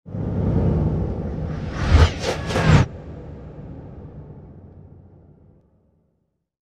mixed-ghost-voices